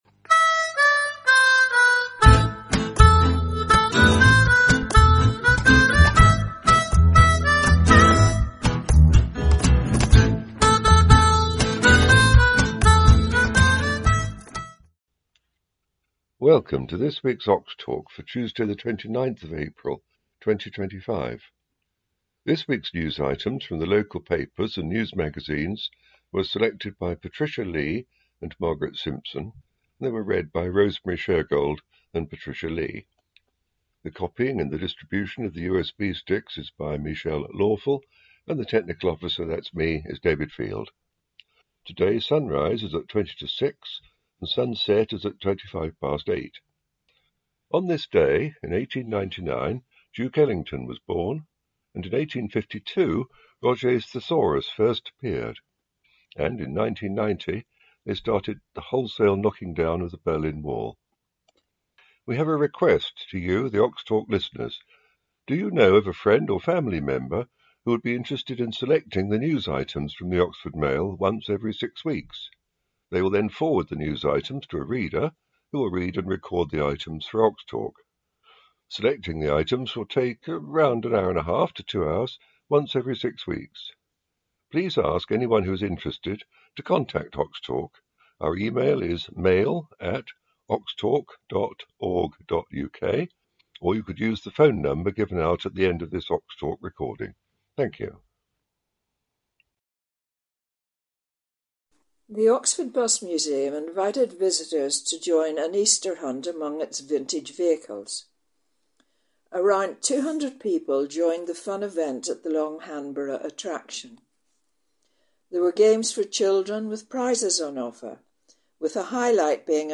Talking News: 29th April 2025